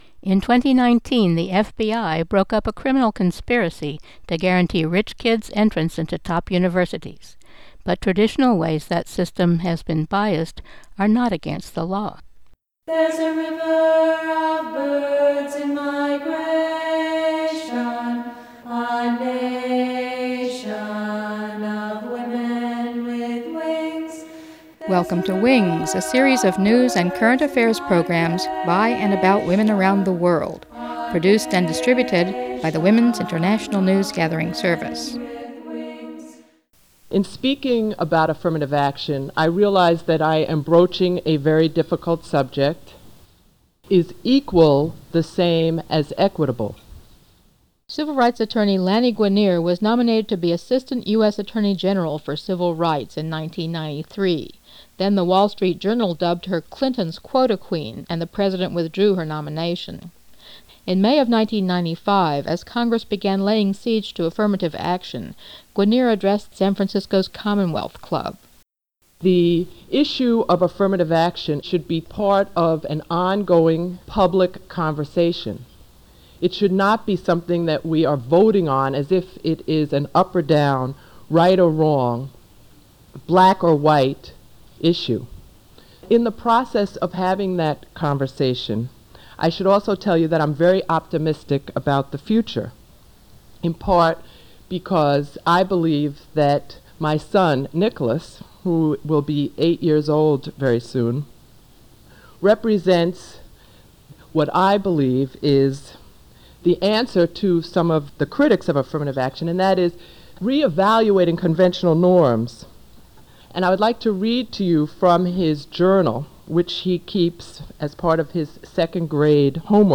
File Information Listen (h:mm:ss) 0:28:50 WINGS13-21AffirmativeAction-28_50-320kbps.mp3 Download (28) WINGS13-21AffirmativeAction-28_50-320kbps.mp3 69,201k 320kbps Mono Comments: Update of Lani Guinier speech to Commonwealth Club of San Francisco (1995) Listen All